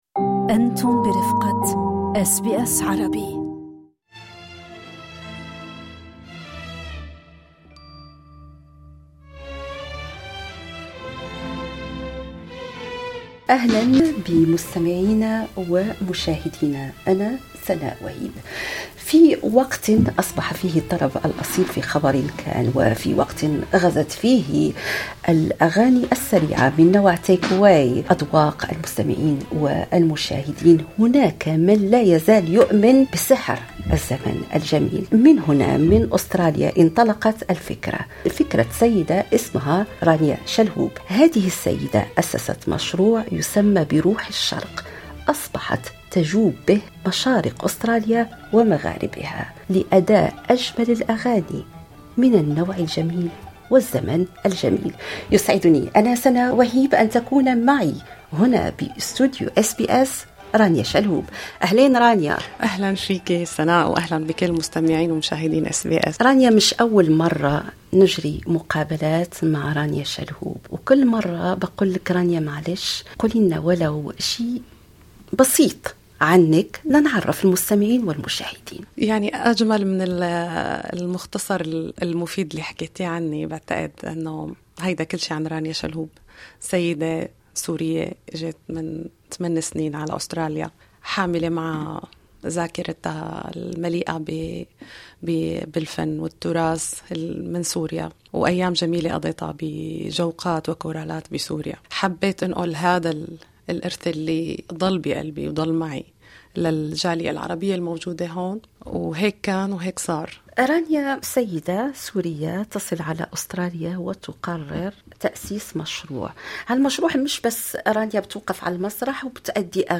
ظهورها كضيفة في استوديوهات SBS أتاح فرصة للغوص أكثر في تجربتها الملهمة ومسيرتها التي تعكس تلاقح الثقافات من خلال الفن.